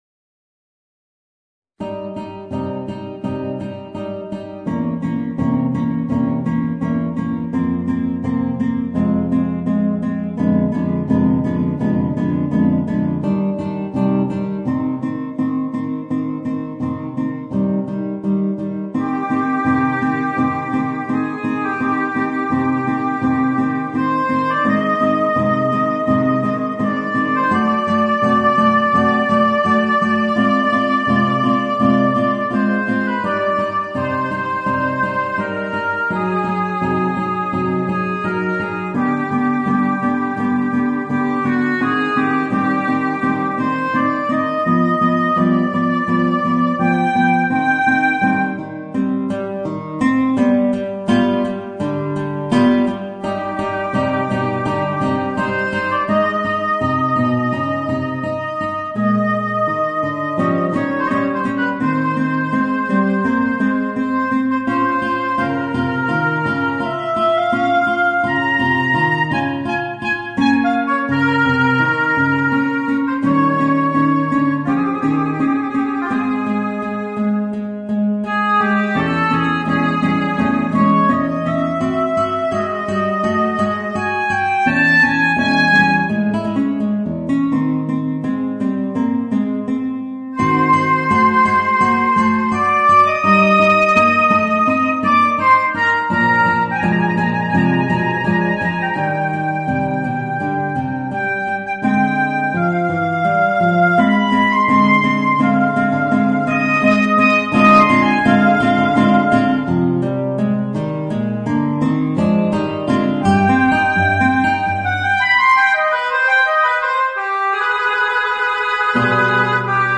Voicing: Guitar and Oboe